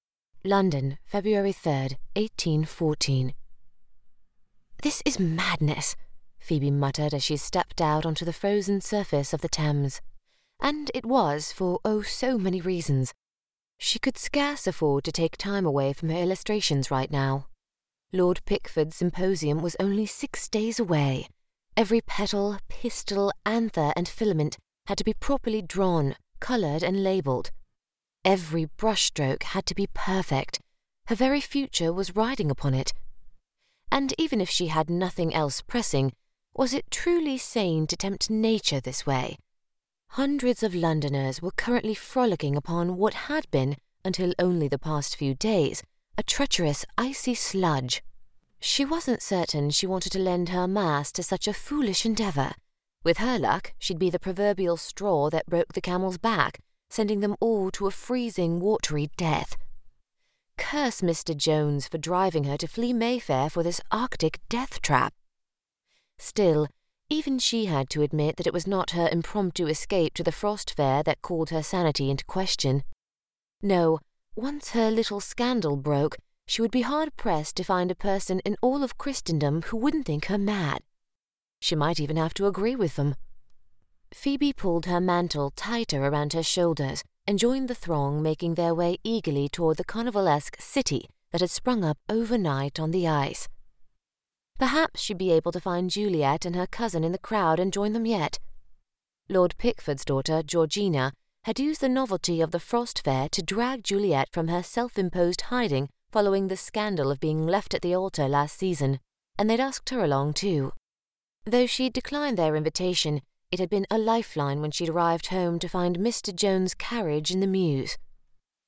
Has Own Studio
STUDIO & EQUIPMENT Whisper Room Isolation Booth Sennheiser 416 microphone Scarlett 2i2 Preamp
british rp | natural